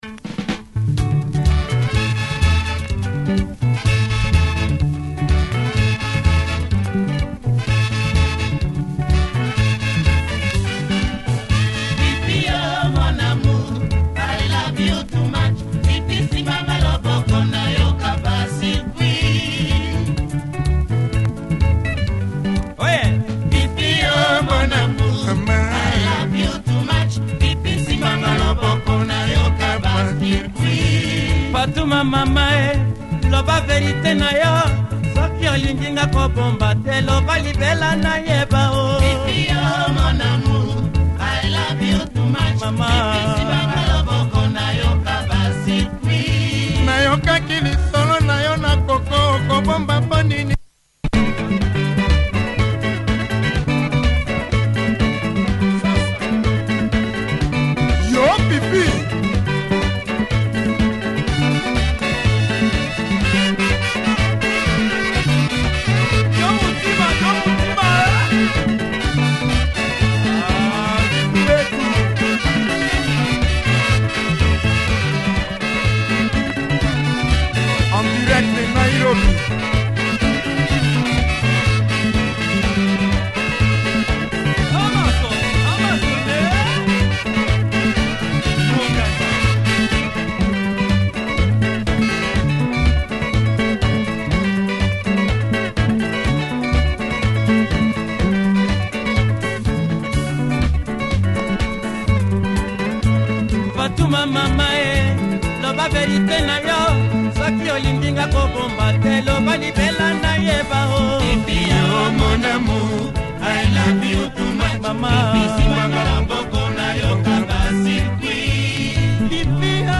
delivering great Soukous music